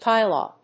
音節pi・lau, pi・law 発音記号・読み方/pɪlˈɔːpíːlɑʊ/